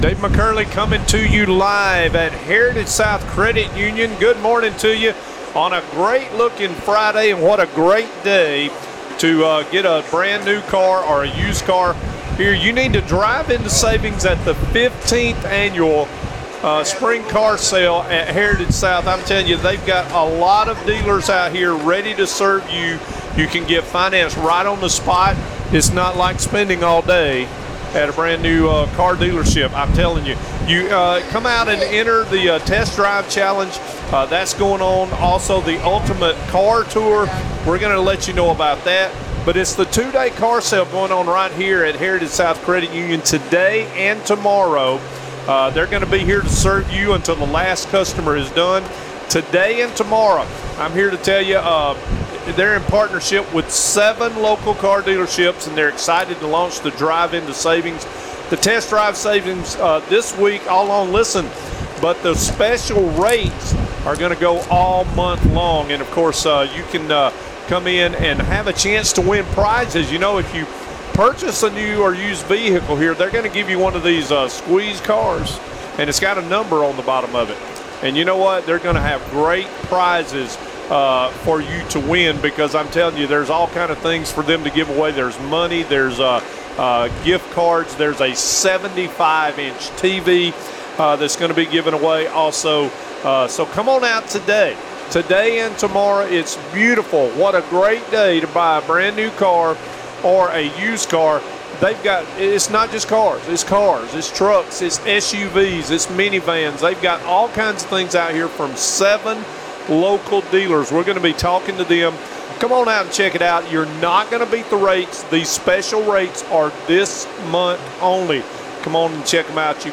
Live from Heritage South Credit Union's 15th Annual Car Sale